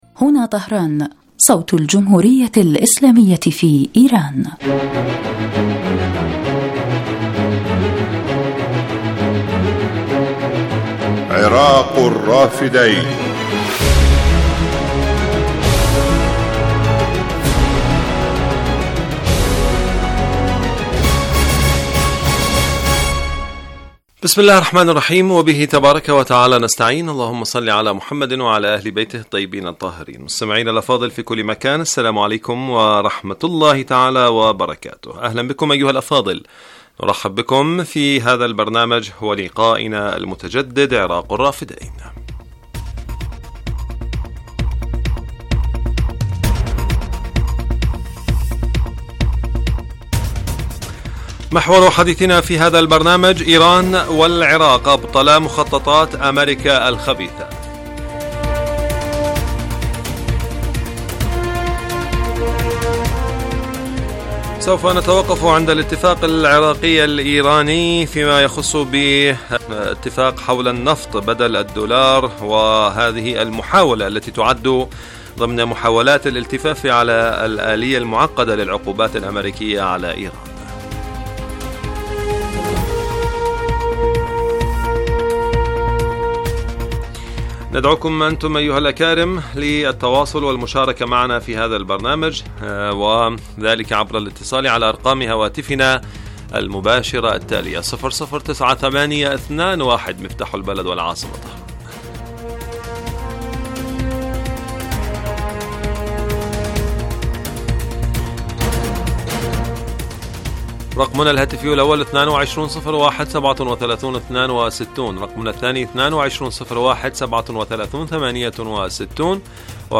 برنامج حي يتناول بالدراسة والتحليل آخرالتطورات والمستجدات على الساحة العراقية وتداعيات على الإقليم من خلال استضافة خبراء سياسيين ومداخلات للمستمعين عبر الهاتف.
يبث هذا البرنامج على الهواء مباشرة أيام السبت وعلى مدى نصف ساعة